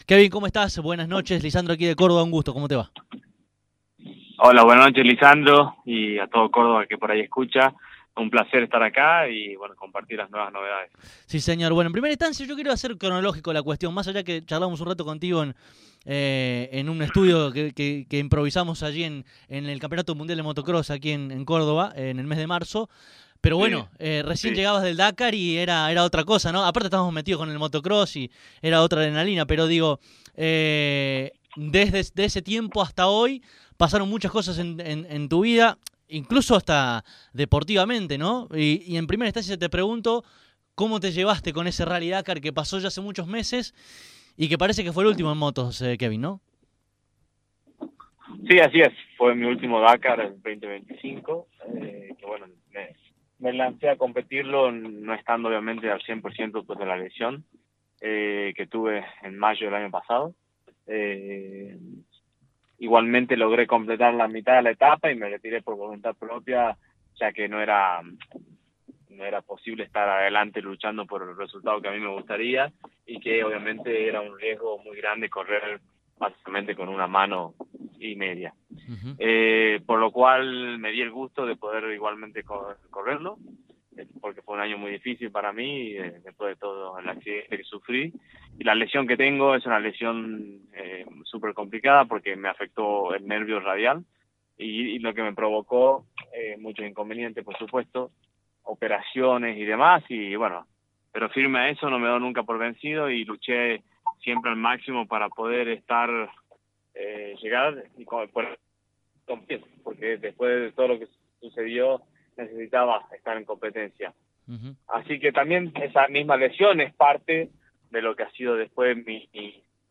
Este jueves, Kevin Benavides dialogó con CÓRDOBA COMPETICIÓN.